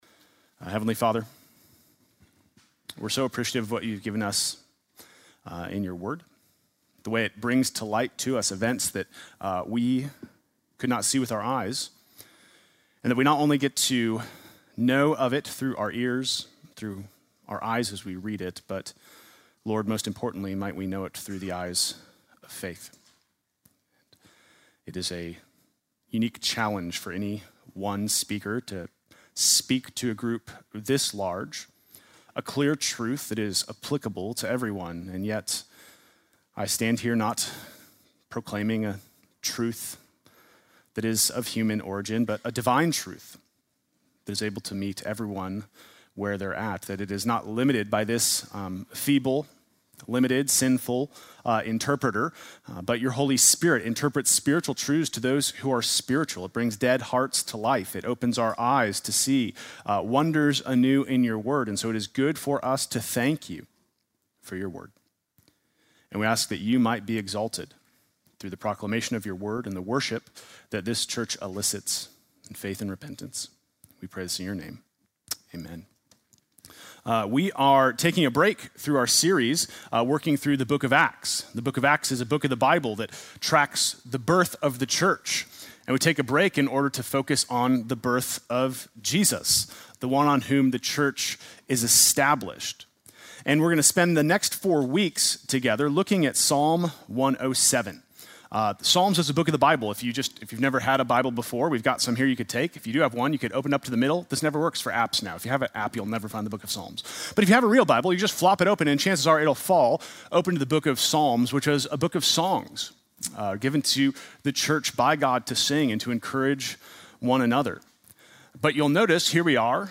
Sunday morning message December 8